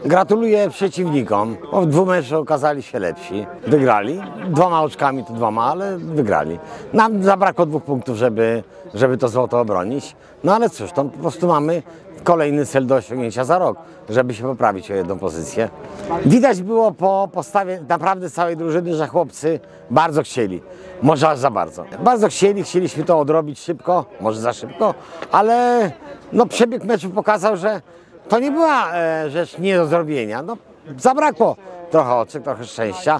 Wypowiedzi po finale: